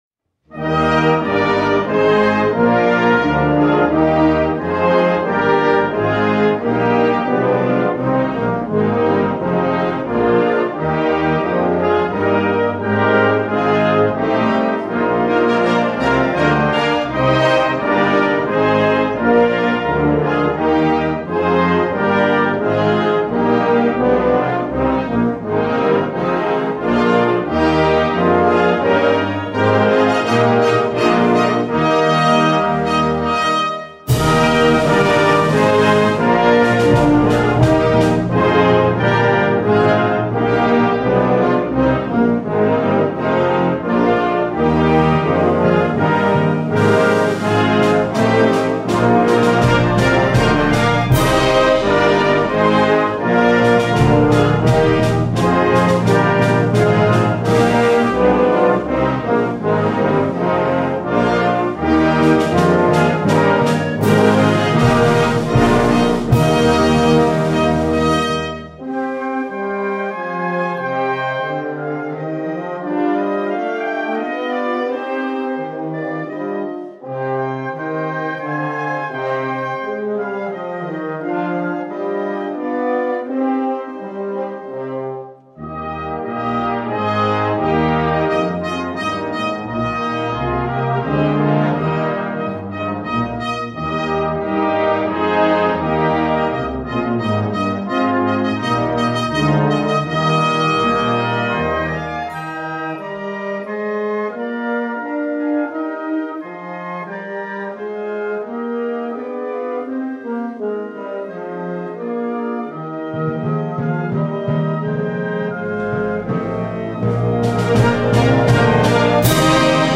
Gattung: Messe für Blasorchester
Besetzung: Blasorchester